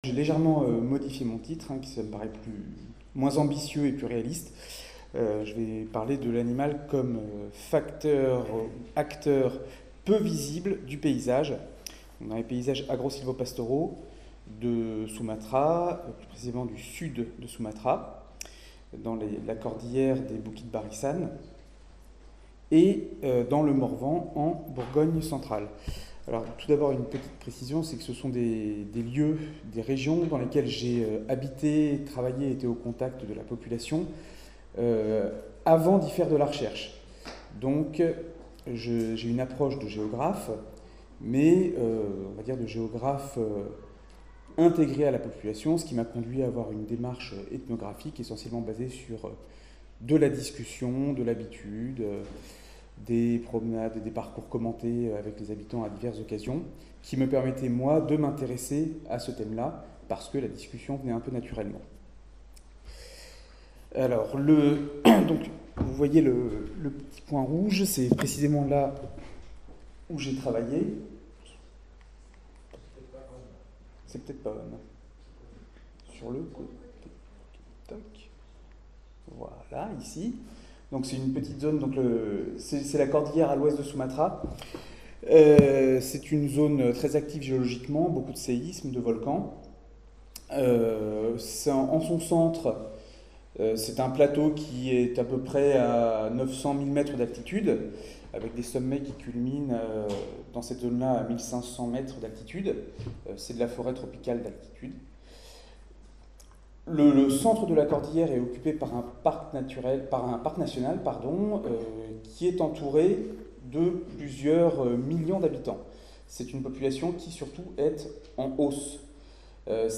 Ce colloque international, qui s'est tenu au sein du Domaine national de Chambord (26 et 27 mars 2019), s'inscrit dans le cadre du projet de recherche COSTAUD (Contribution des OnguléS au foncTionnement de l’écosystème et AUx services rendus à ChamborD, financé par la Région Centre-Val de Loire et porté par l'Irstea, 2016-2019).